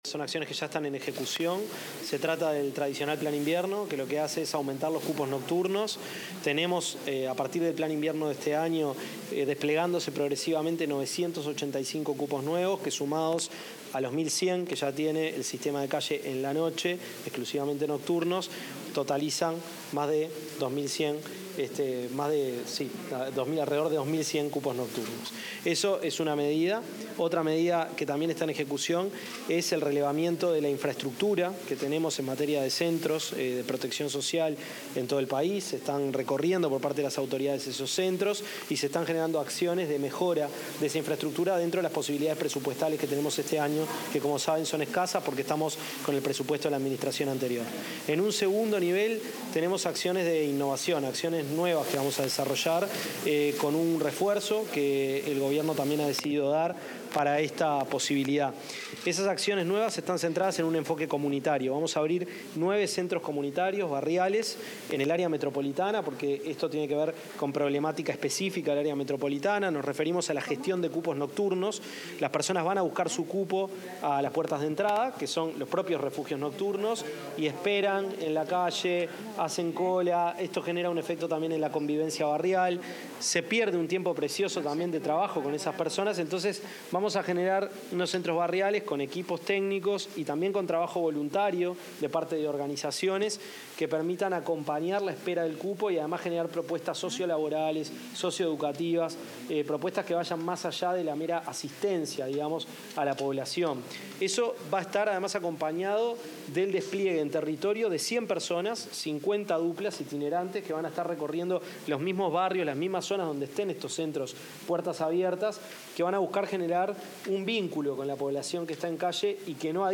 Declaraciones a la prensa del ministro de Desarrollo Social, Gonzalo Civila.
El ministro de Desarrollo Social, Gonzalo Civila, dialogó con la prensa luego de la presentación de una serie de iniciativas para la atención de
conferenciaMides.mp3